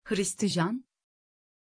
Aussprache von Hristijan
Türkisch
pronunciation-hristijan-tr.mp3